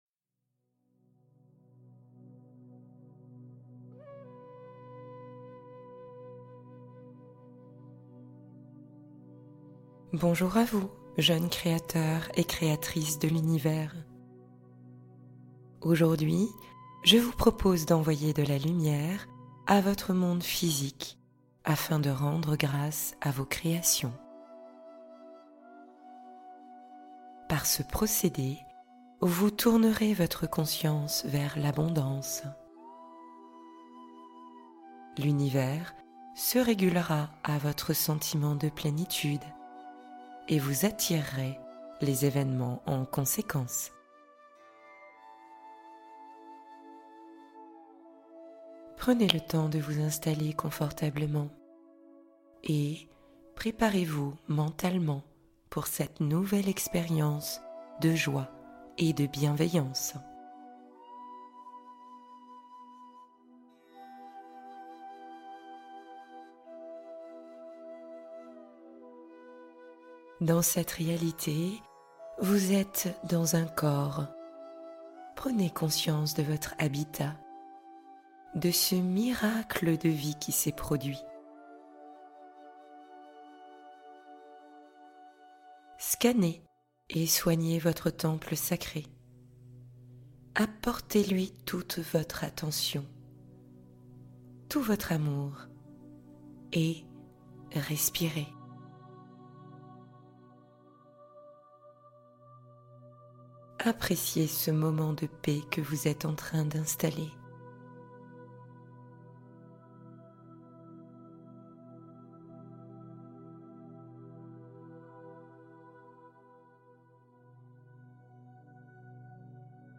Suivez la lumière de votre chemin | Méditation d’élévation spirituelle